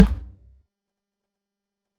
ai_jump_dirt.wav